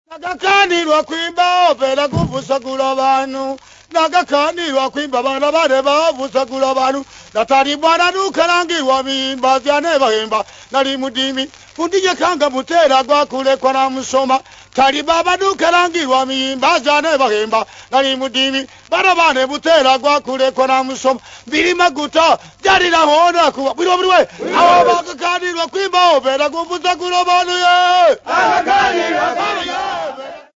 Folk music
Field recordings
sound recording-musical
Stage fright is not the exclusive experience of any one people, it would appear. Topical sogn with clapping.